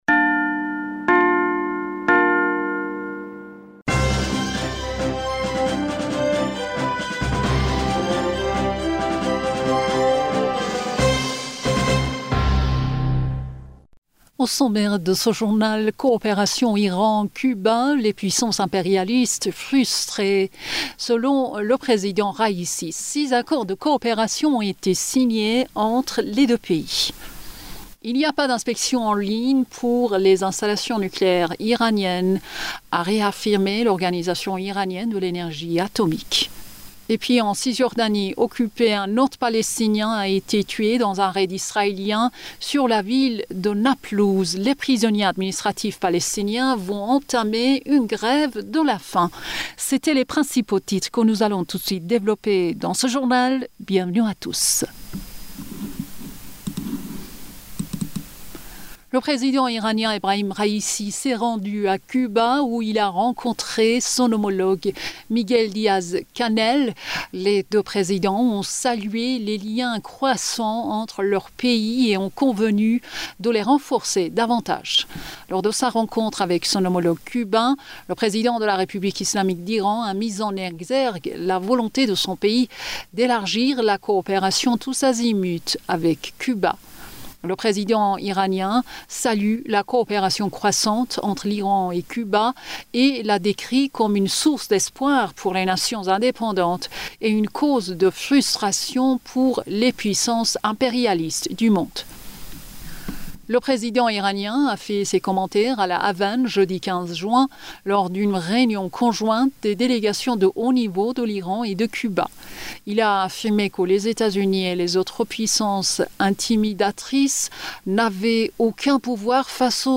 Bulletin d'information du 16 Juin 2023